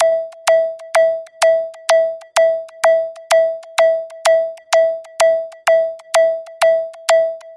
Звуки радара
Сигнал нарастает